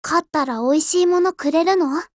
Cv-50203_warcry.mp3